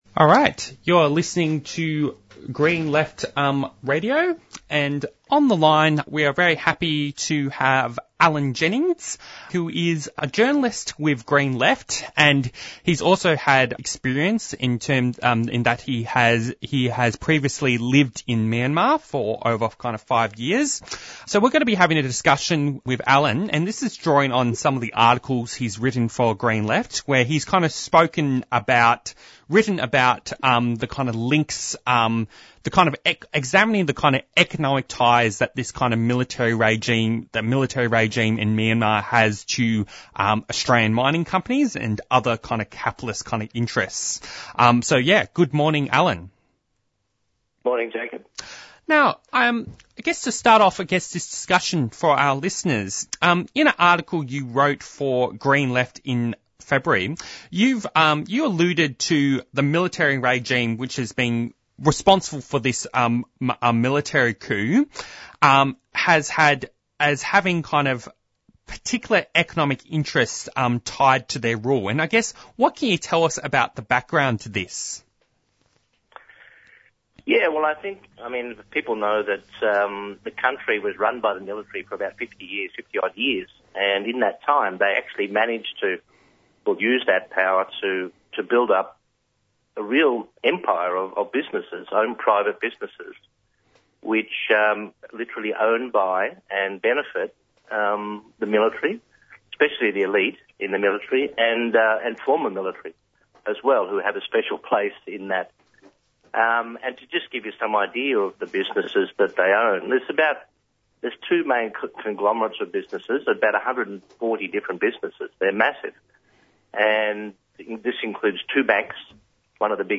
Newsreports McCormick workers in Clayton win pay rise retain conditions after more than six weeks on strike.
Interviews and Discussion